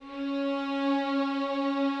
Added more instrument wavs
strings_049.wav